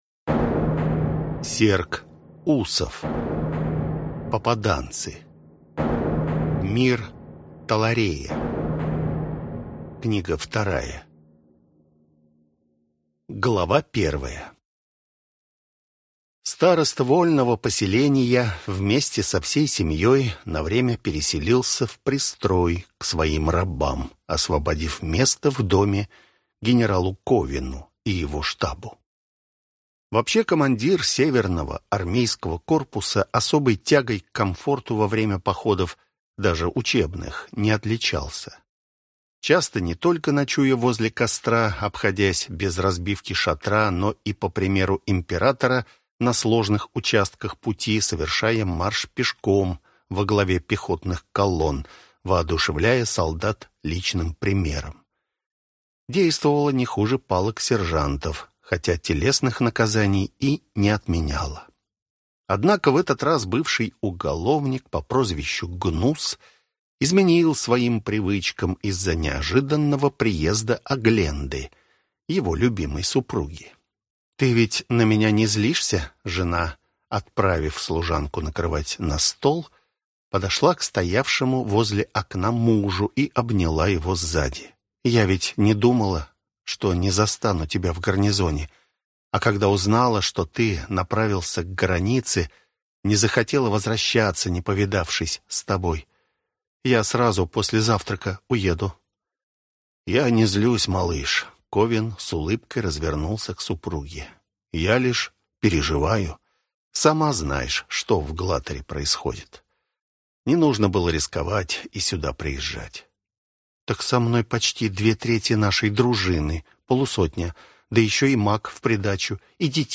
Аудиокнига Попаданцы. Мир Таларея. Книга 2 | Библиотека аудиокниг